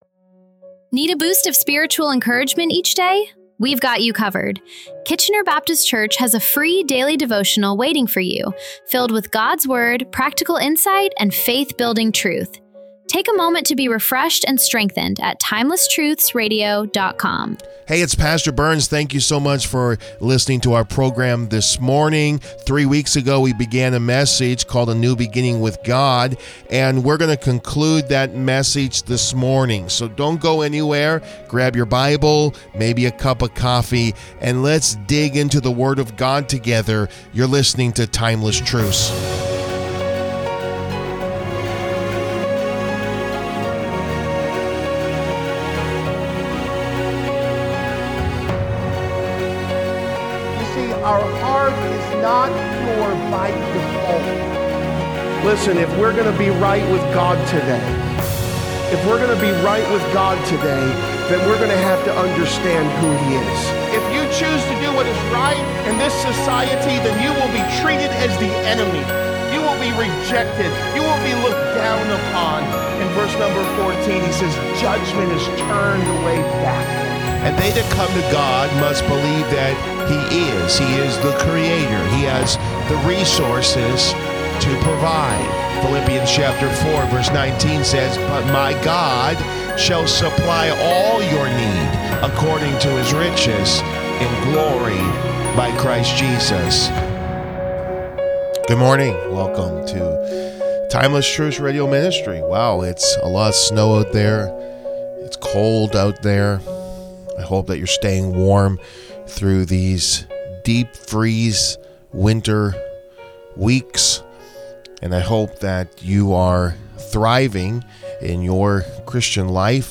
This sermon also challenges us to recognize that real change begins on the inside.